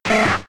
Cri de Rattata K.O. dans Pokémon X et Y.